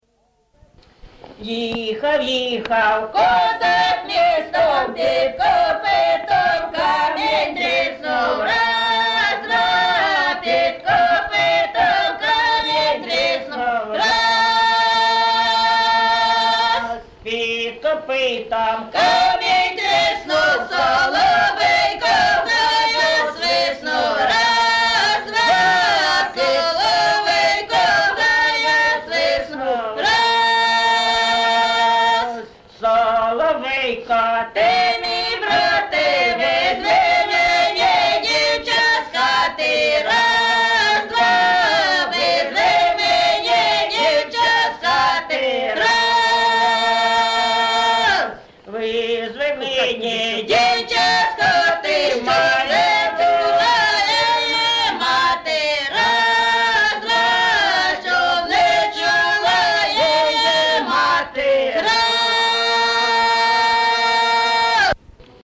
ЖанрКозацькі
Місце записус. Яблунівка, Костянтинівський (Краматорський) район, Донецька обл., Україна, Слобожанщина